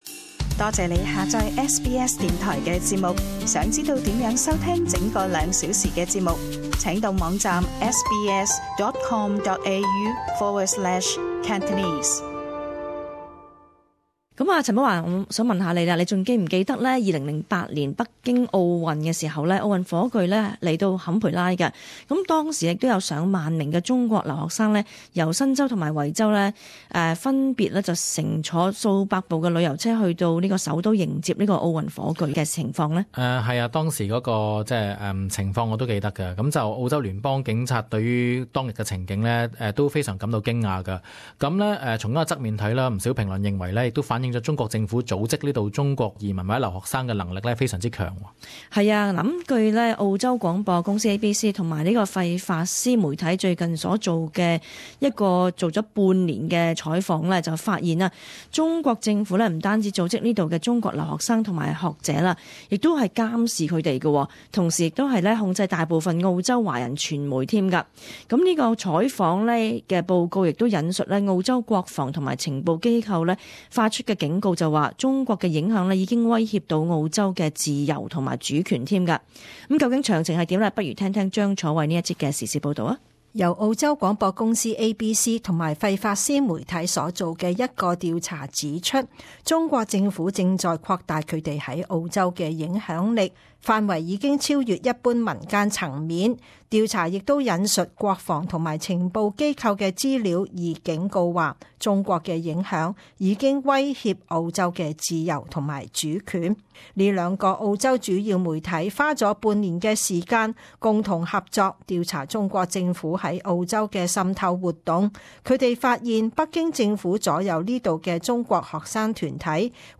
【時事報導】中國監控在澳華人及中文媒體